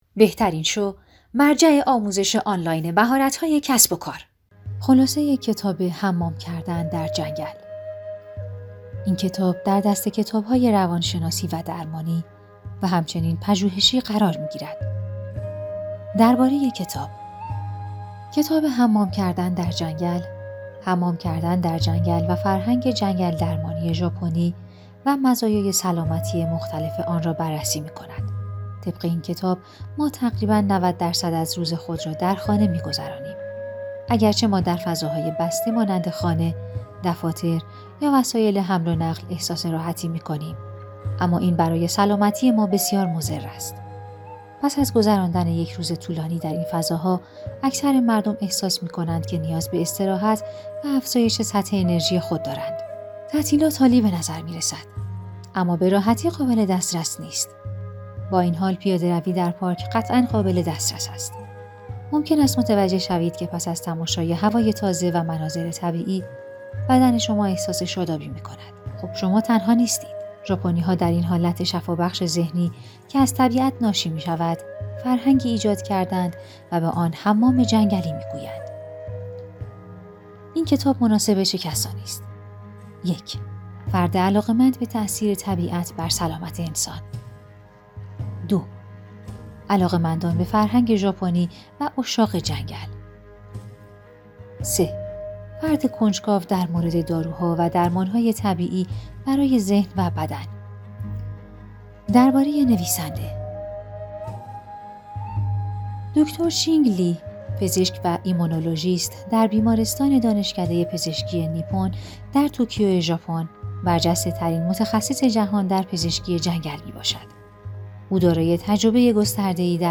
کتاب صوتی موجود است